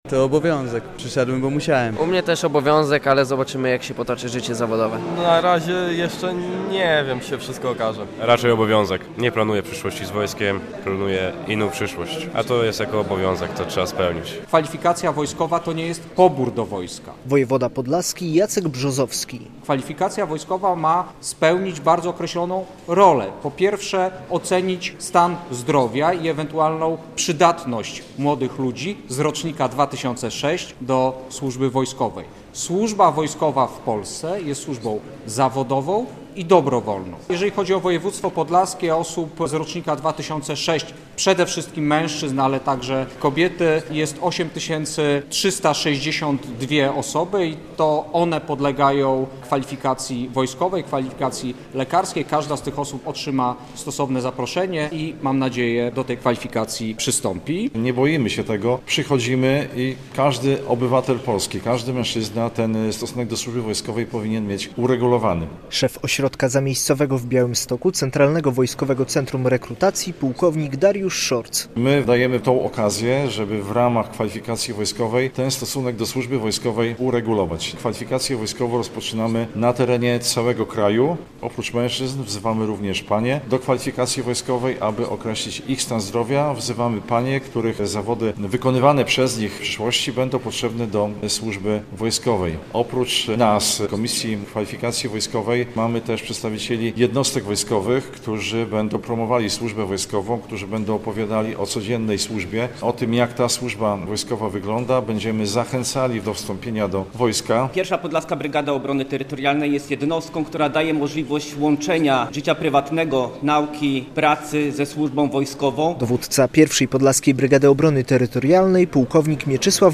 Rozpoczęła się kwalifikacja wojskowa - relacja
W poniedziałek (3.02) młodzi mężczyźni byli badani w Hajnówce.